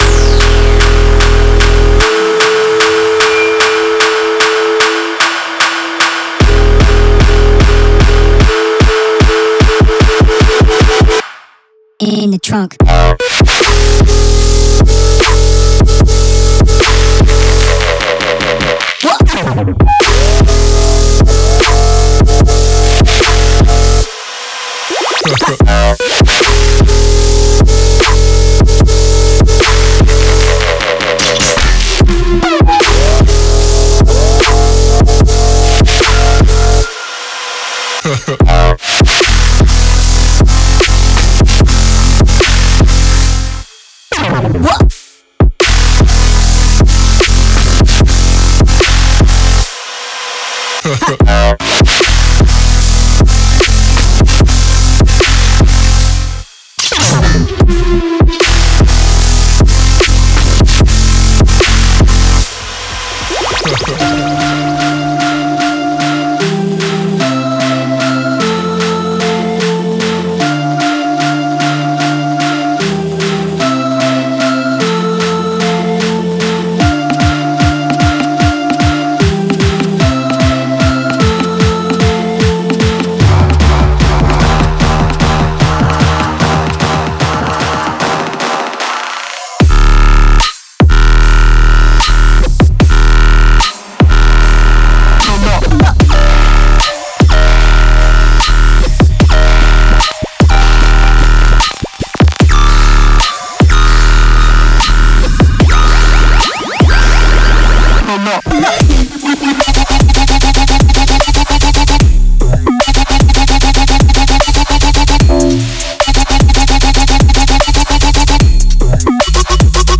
Genre:Dubstep
メロディック要素としては、壮大なシンセリード、アルペジオシンセ、リッチなコード、煌びやかなベルサウンドを収録。
デモサウンドはコチラ↓
24Bit 44.1KHZ